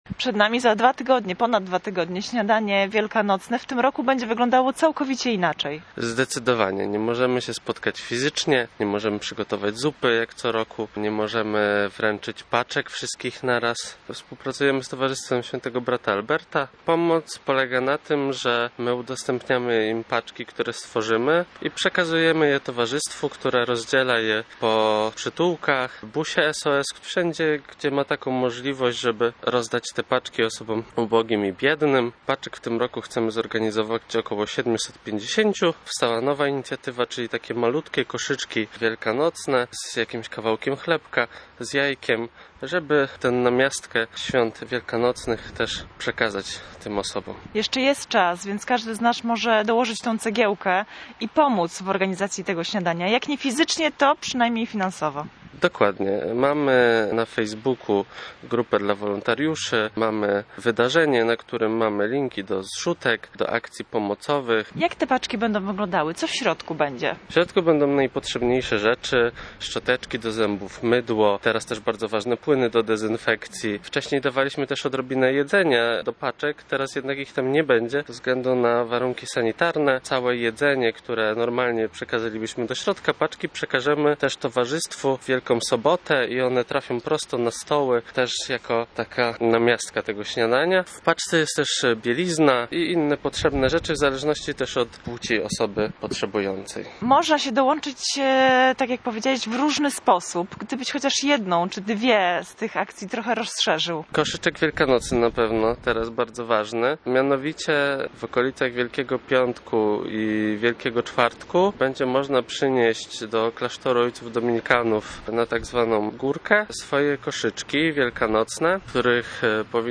O szczegółach tegorocznej organizacji Śniadania Wielkanocnego dla ubogich i dotkniętych kryzysem bezdomności w naszej rozmowie